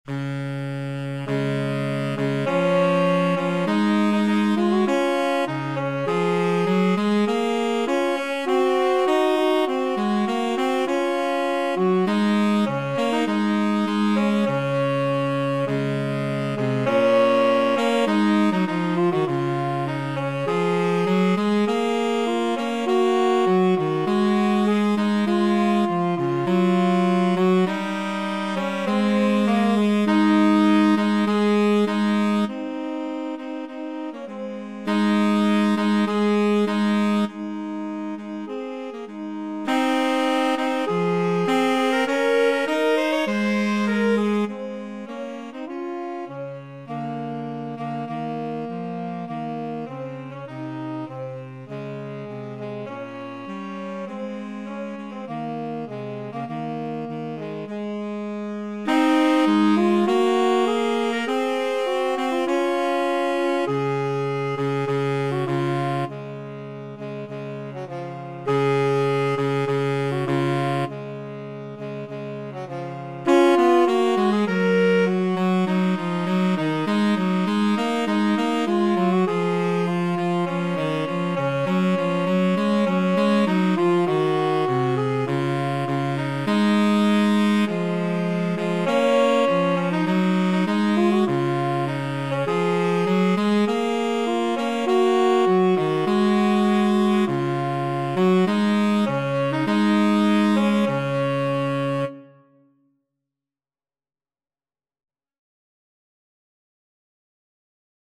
4/4 (View more 4/4 Music)
Classical (View more Classical Tenor Sax Duet Music)